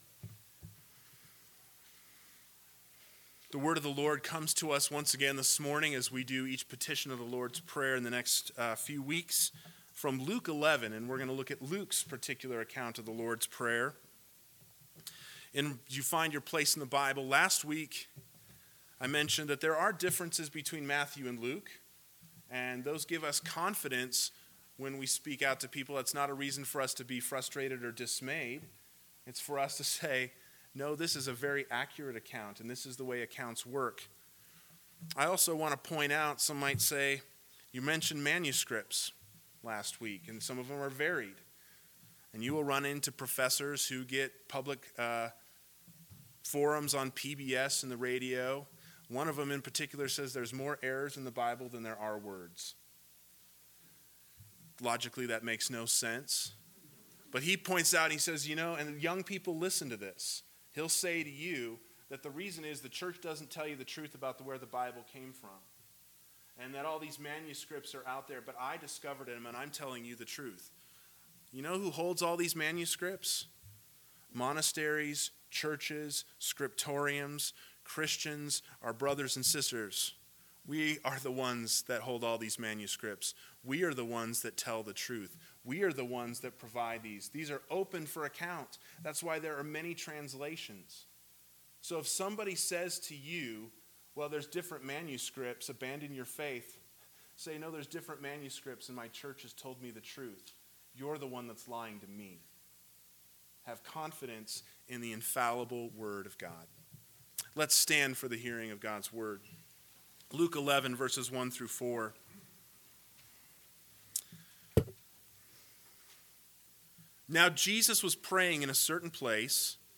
AM Sermon – 07/12/2020 – Luke 11:1-4 – What’s in a Name?